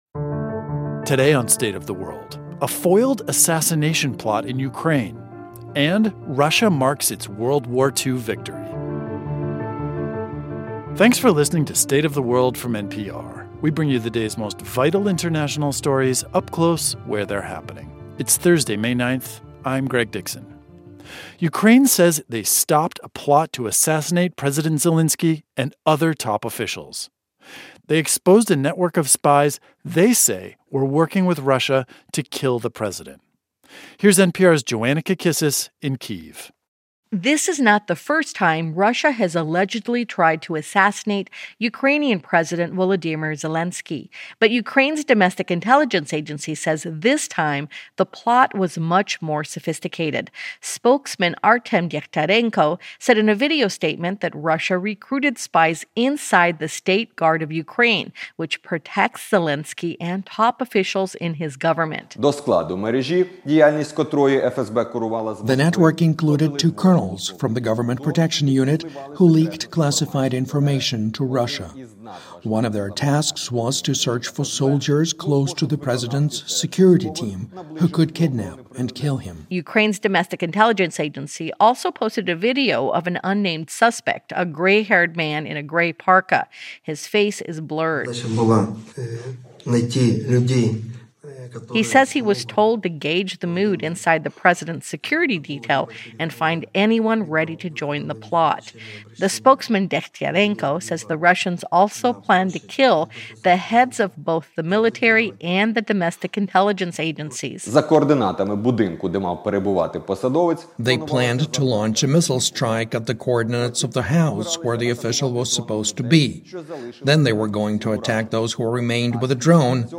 Ukraine uncovers what they say was a sophisticated network of spies working with Russia to kill President Volodymyr Zelenskyy and other top officials. And in Russia, during a celebration marking the USSR's victory over Germany in World War II, Russia's president again drew parallels between that fight against fascism and the war in Ukraine. We hear from Russians who attended the event.